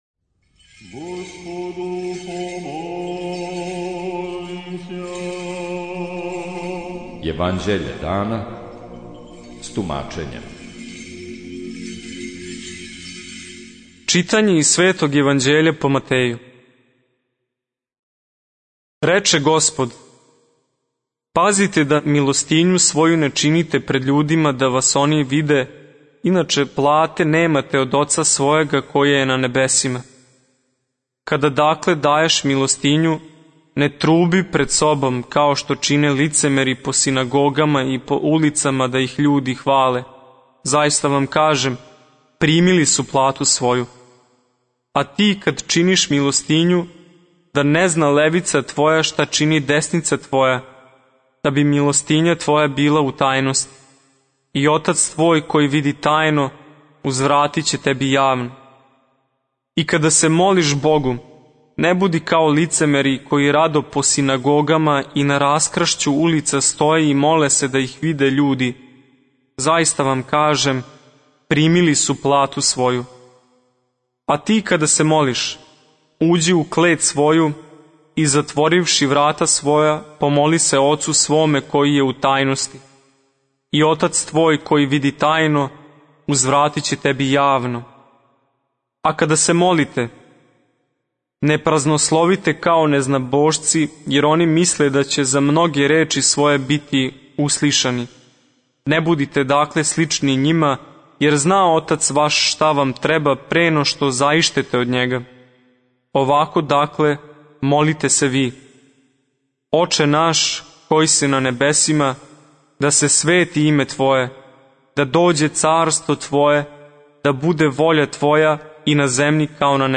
Читање Светог Јеванђеља по Матеју за дан 16.09.2023. Зачало 97.